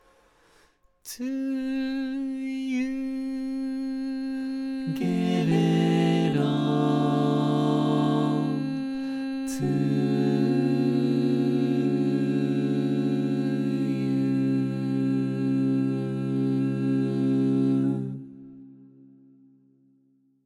Key written in: C Major
How many parts: 4
Type: Barbershop
All Parts mix:
Learning tracks sung by